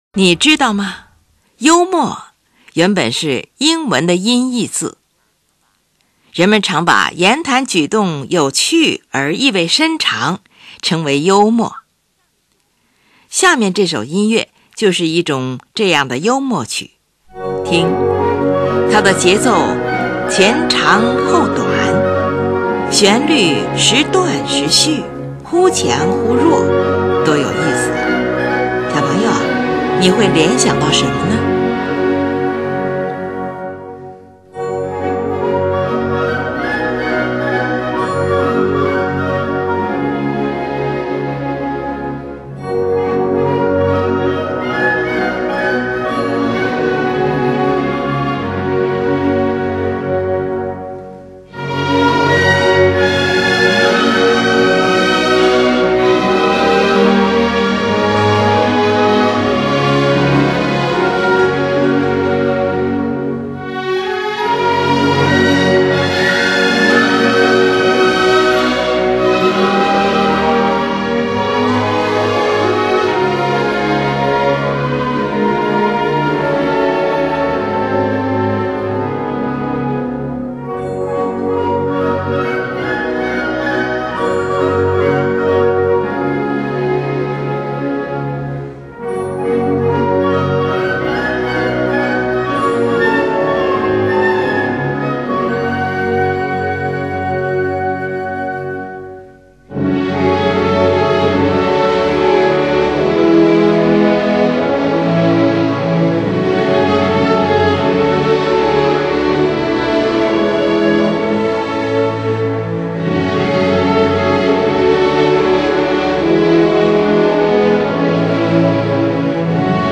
听，它的节奏前长后短，旋律时断时续、忽强忽弱，多有意思呀！
这首曲子由对比鲜明的两部分构成。
反复一遍之后，音乐经过一个全乐队齐奏的过渡句，随后出现的是与前面主题相对比的轻柔主题，优美动听。
情绪随着小提琴音区的变高而更趋热烈。